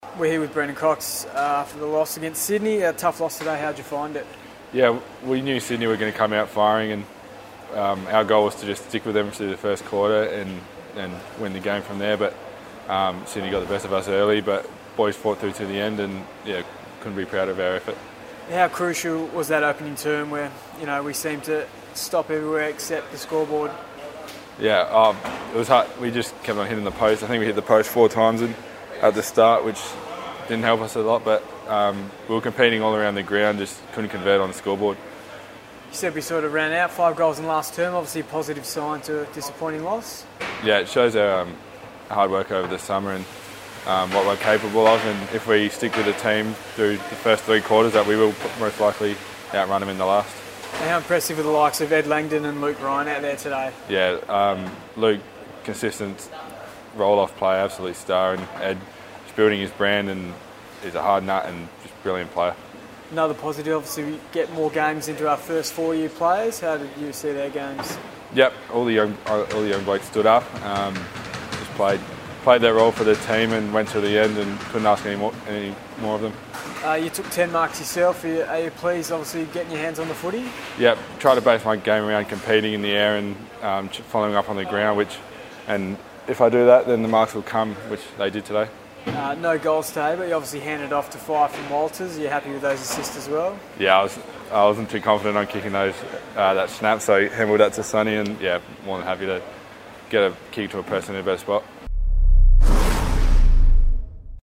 Brennan Cox post-match interview - Round 9 v Swans